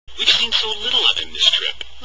Another case is that if /f/ is followed with /h/, the /h/ sound disappear, but the vowel after /h/ will move over to link with /v/ (originally /f/); number 9 sentence below serves as a good example.